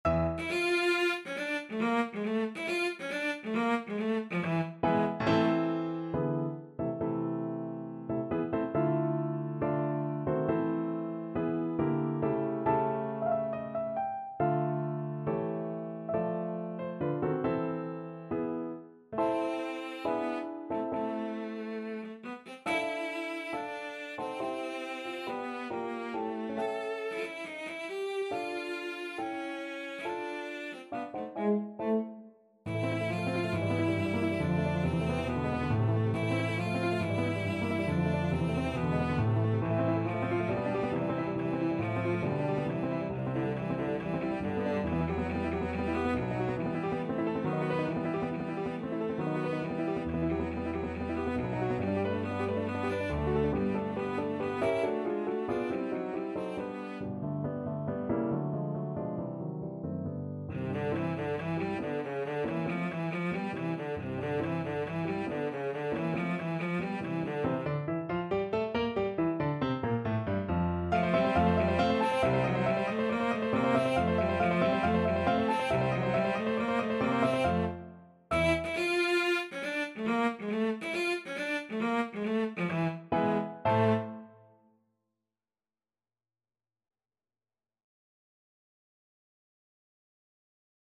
Cello version
4/4 (View more 4/4 Music)
Classical (View more Classical Cello Music)